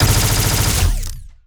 Plasmid Machinegun
GUNAuto_Plasmid Machinegun Burst_05_SFRMS_SCIWPNS.wav